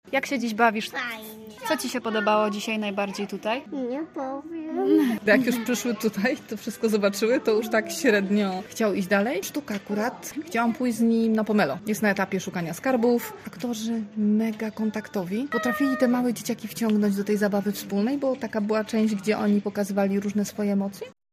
Pierwsi uczestnicy opisują już swoje wrażenia.